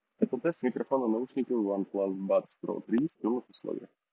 Шумы удаляет хорошо, однако вместе с ними и часть голоса.
В шумных условиях:
oneplus-buds-pro-3-shum.m4a